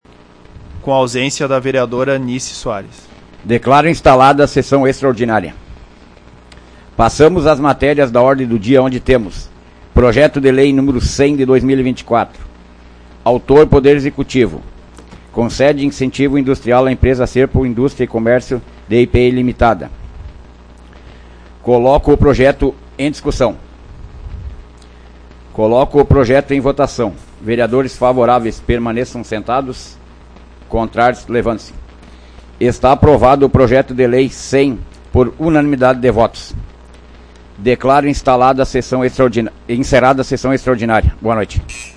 Áudio da 99ª Sessão Plenária Extraordinária da 16ª Legislatura, de 09 de dezembro de 2024